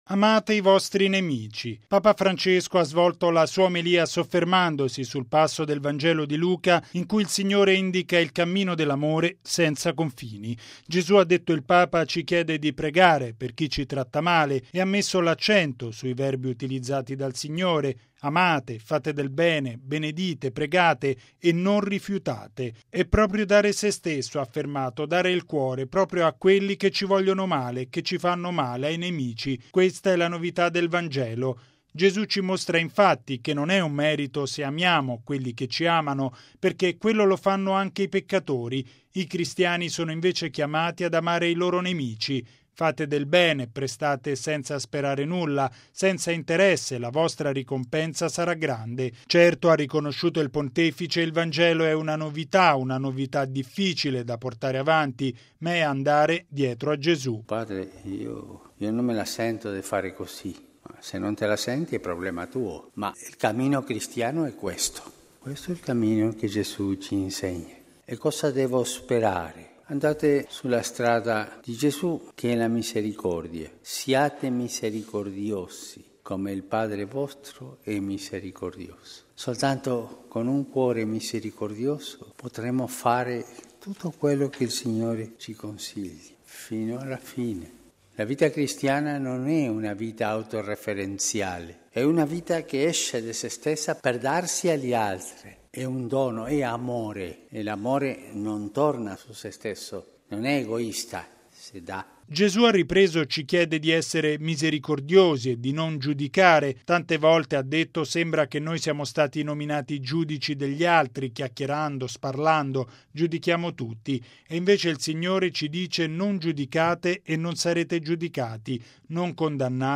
Solo con un cuore misericordioso potremo davvero seguire Gesù. E’ quanto affermato da Papa Francesco nella Messa mattutina a Casa Santa Marta.